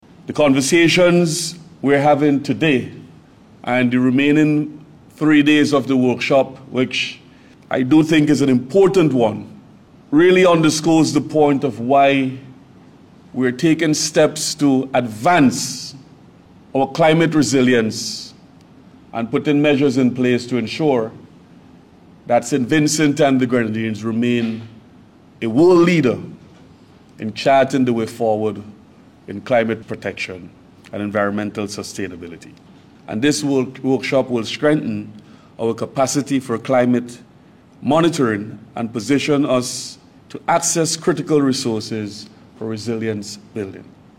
Minister of Sustainable Development, Carlos James, stressed the importance of the workshop which will address the challenges of climate change.